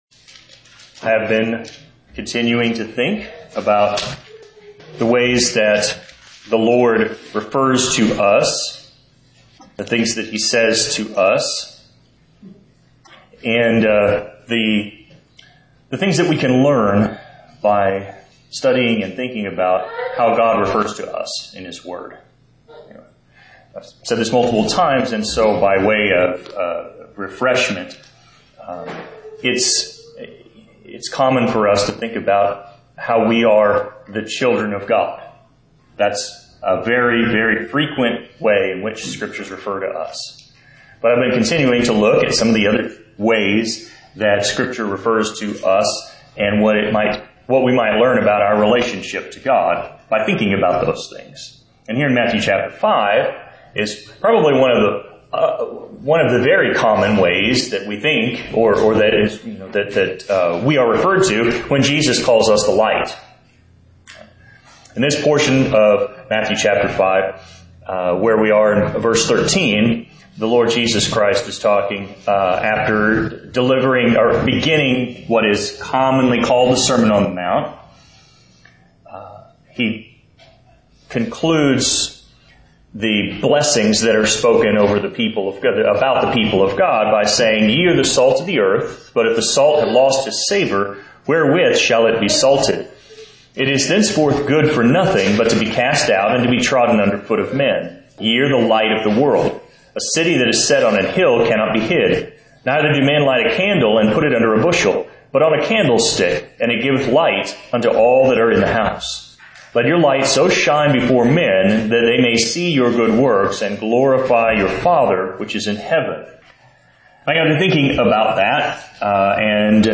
Sermons preached in other churches • Page 3